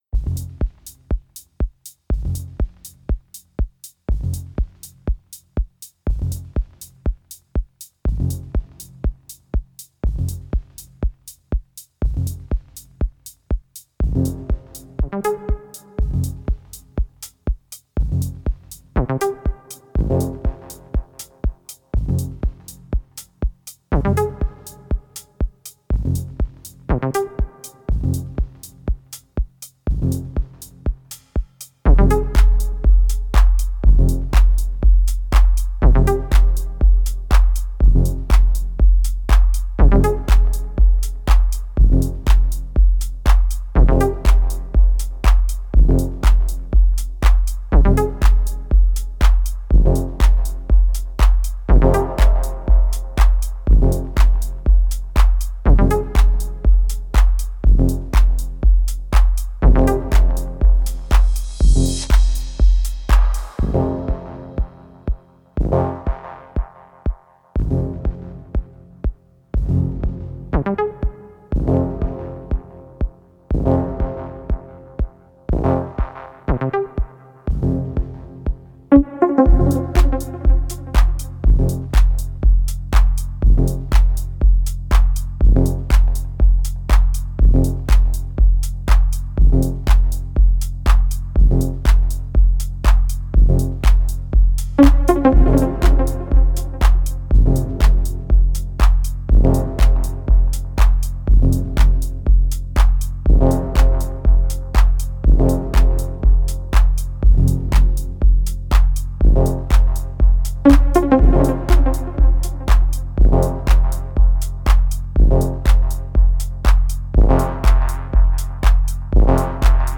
Genre: Deep House/Deep Techno.